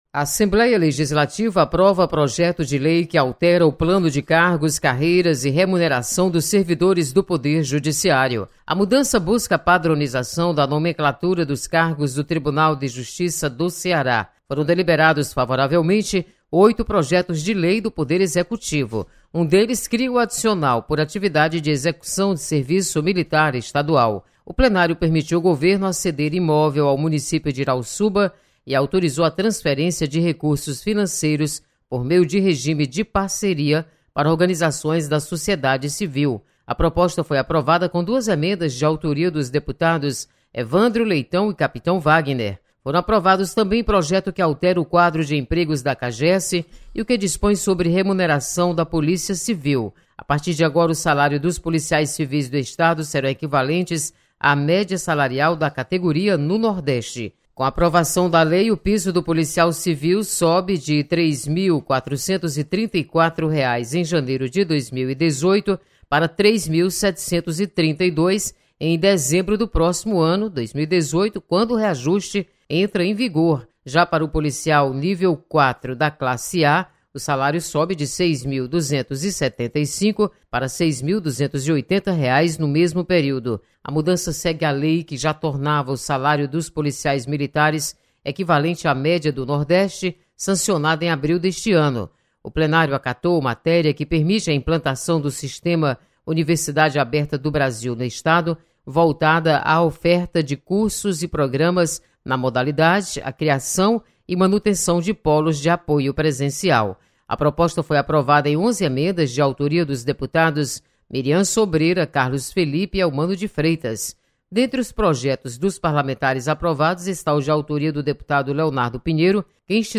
Assembleia aprova alteração no Plano de Cargos e Carreiras dos servidores do Poder Judiciário. Repórter